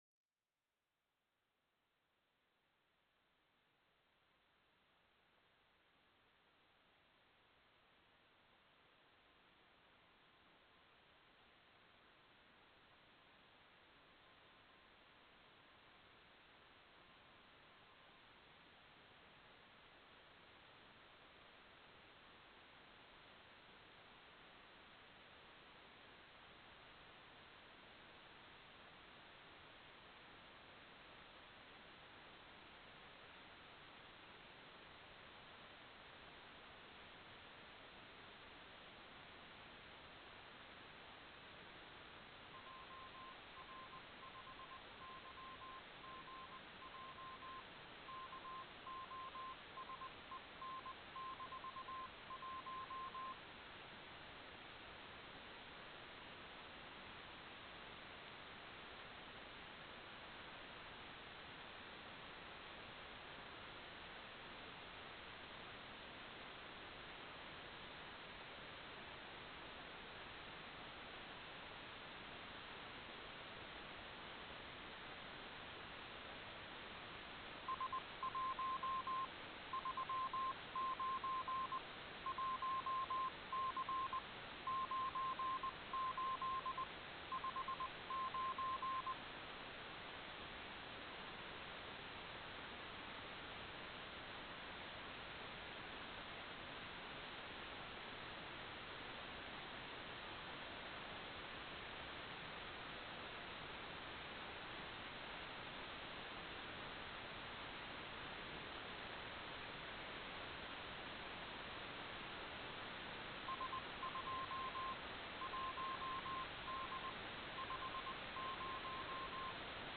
"transmitter_description": "Mode U - CW Beacon",
"transmitter_mode": "CW",